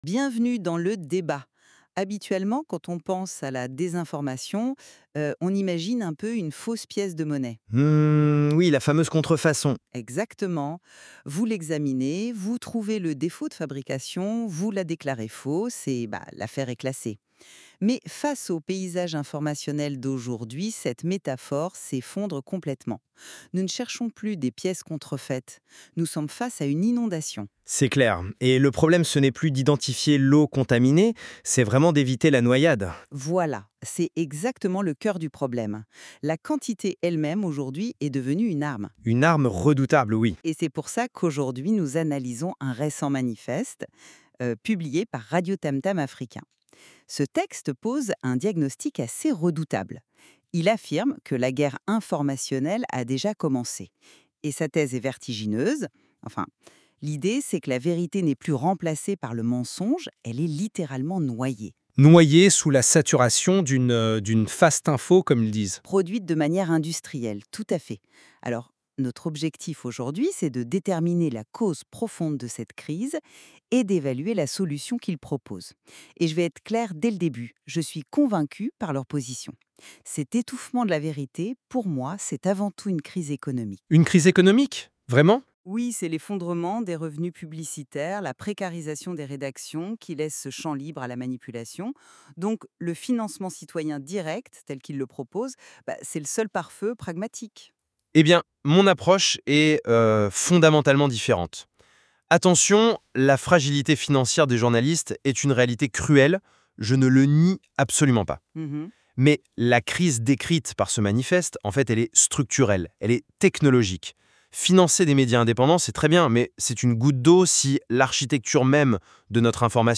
OUTRO (musique montante)